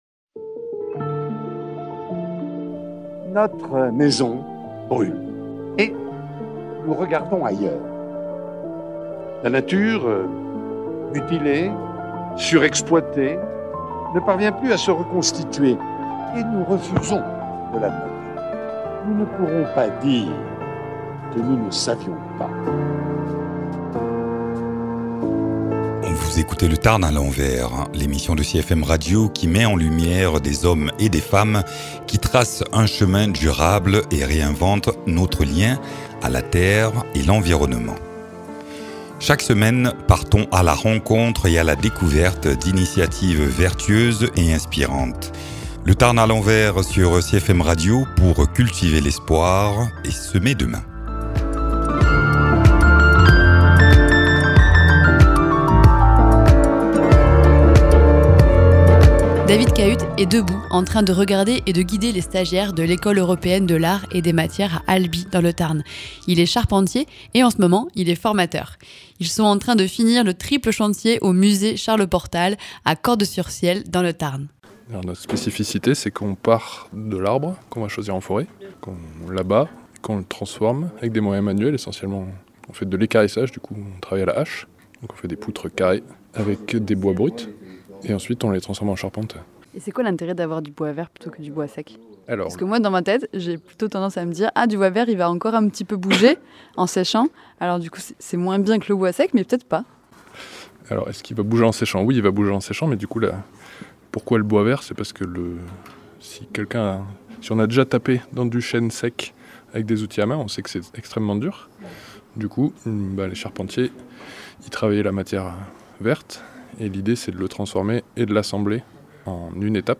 trois stagiaires